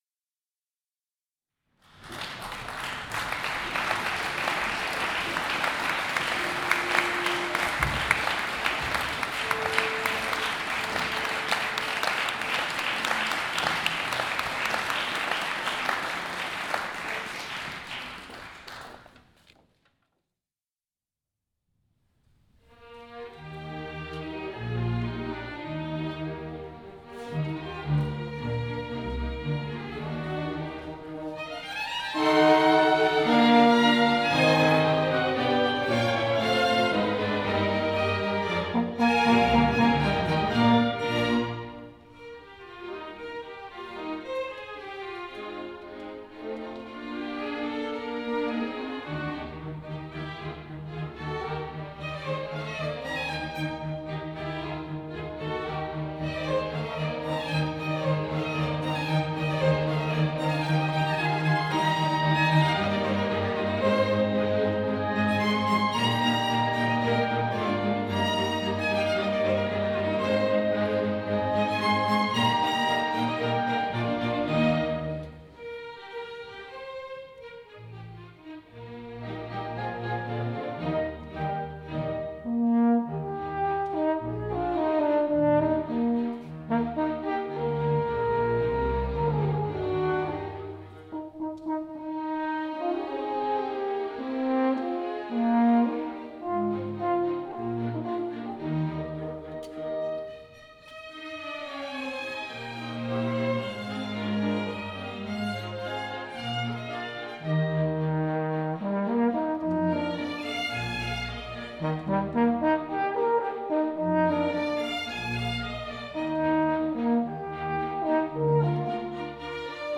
Konzerte Orchesterverein Widnau Hier finden Sie die Aufnahmen unserer Konzerte seit 2010.